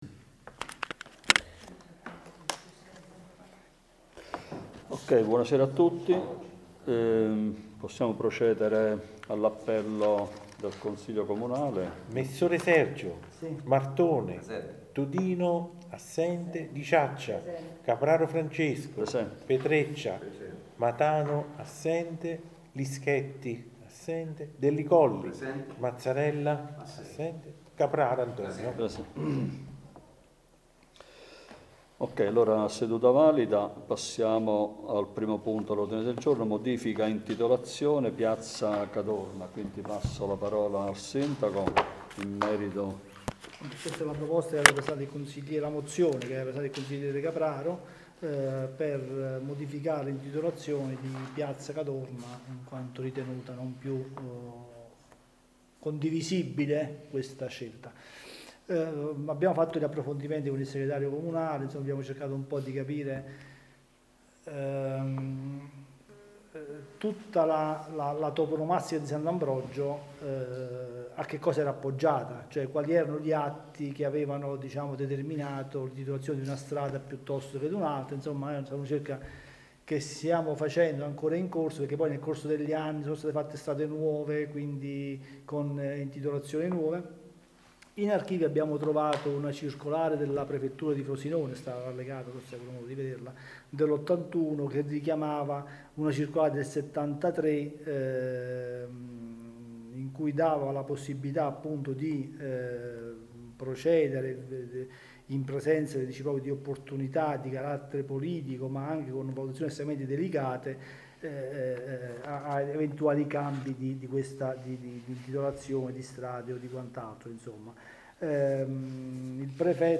Registrazione seduta consiliare del 22.10.2025